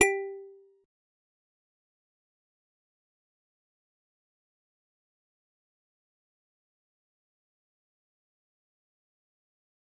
G_Musicbox-G4-pp.wav